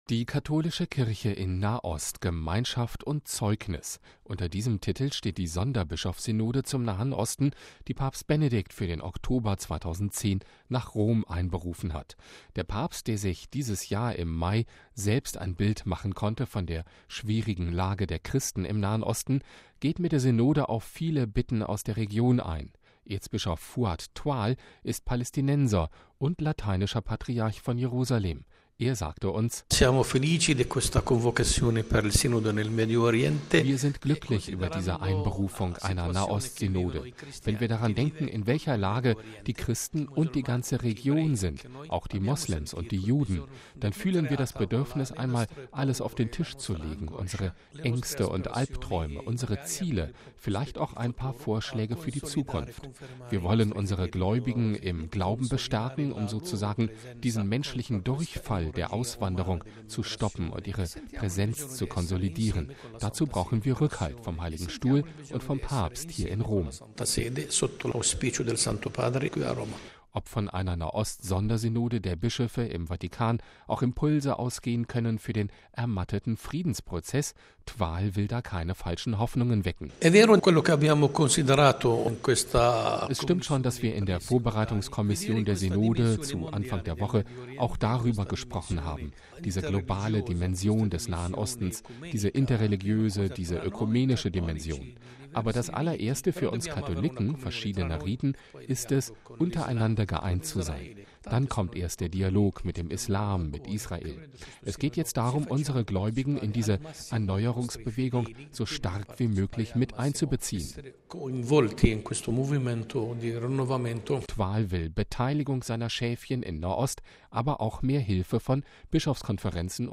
Erzbischof Fouad Twal ist Palästinenser und Lateinischer Patriarch von Jerusalem.